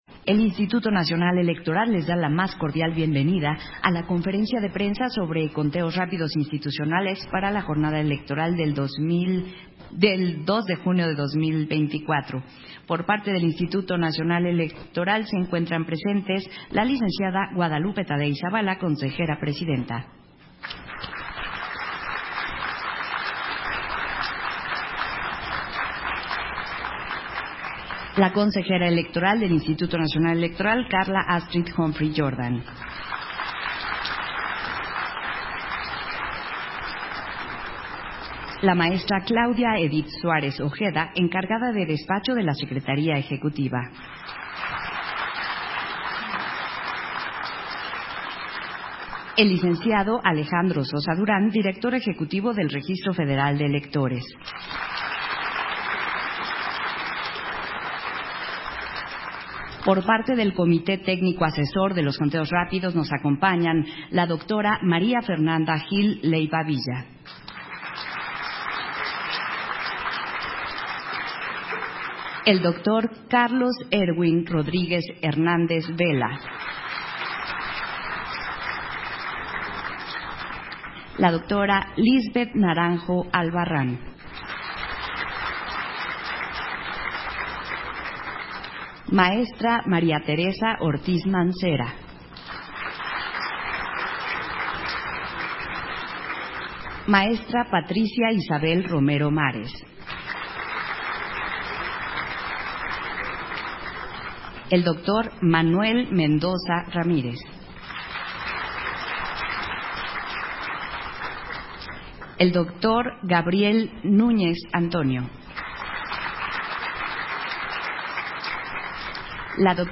170524_AUDIO_CONFERENCIA-DE-PRENSA - Central Electoral